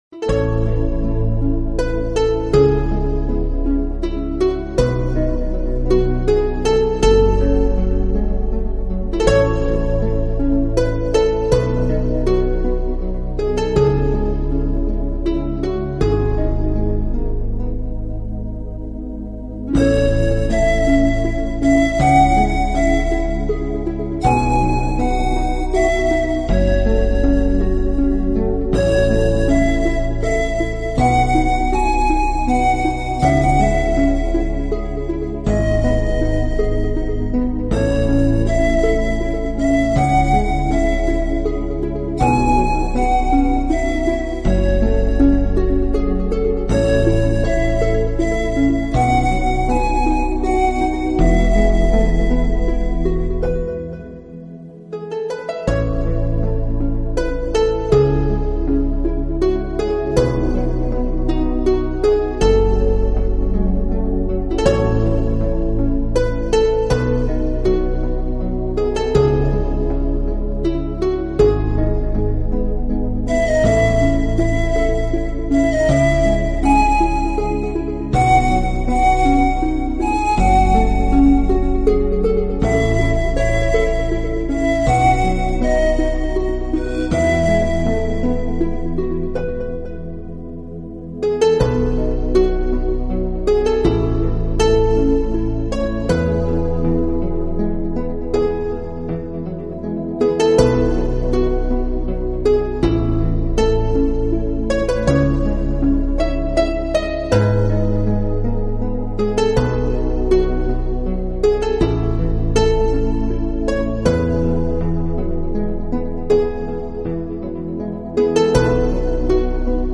在音乐的安静里慢慢舒适和沉静